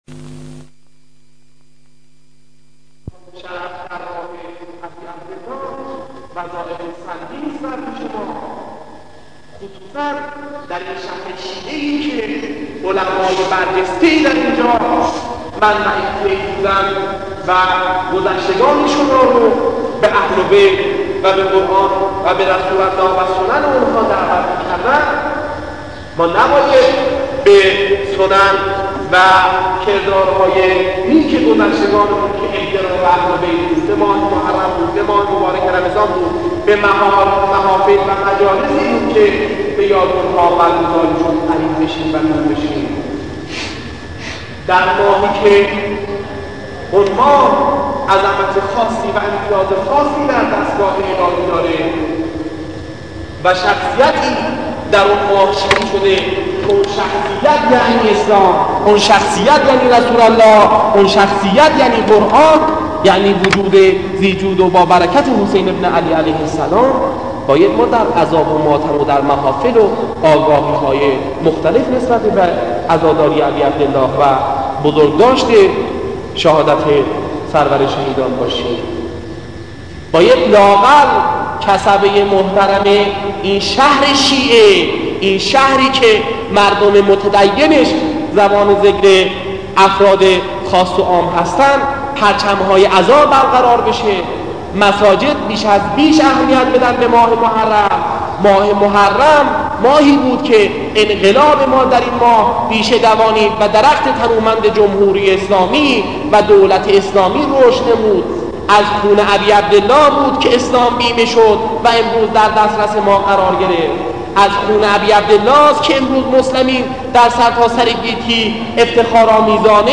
وعظ و خطابه